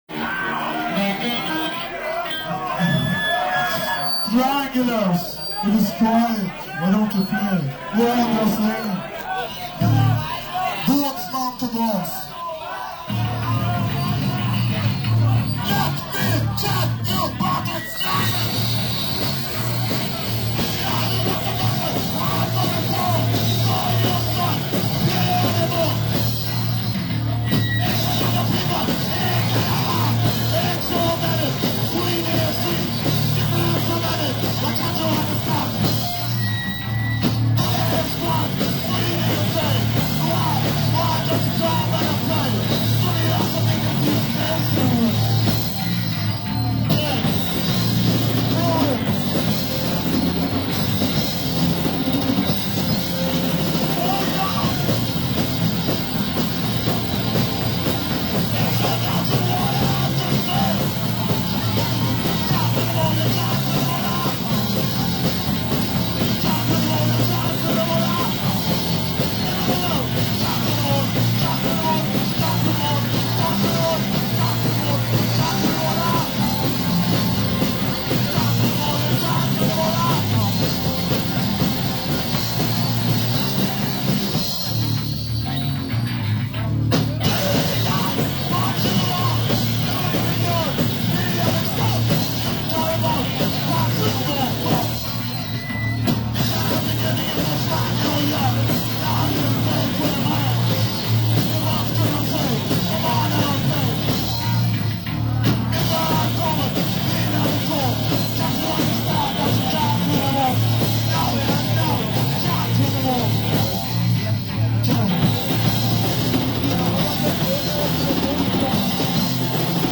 Live på 4:an 79-01-25